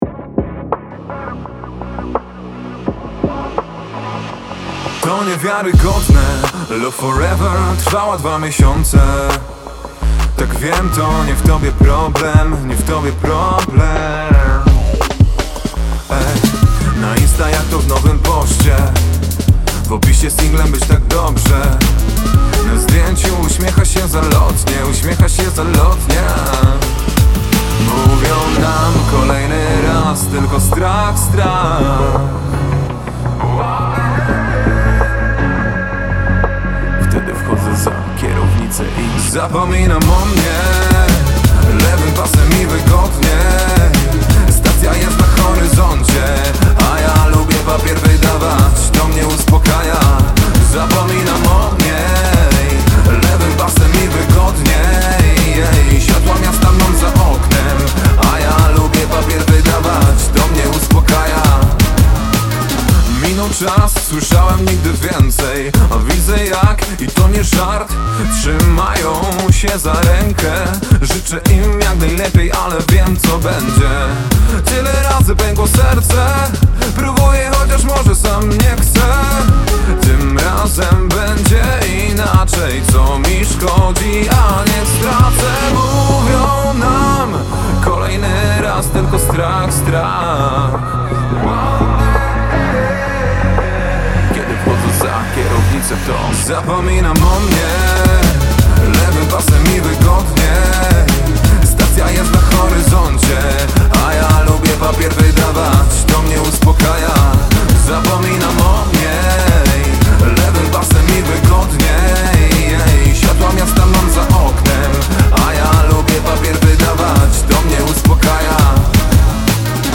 Singiel (Radio)
Drum’N’bass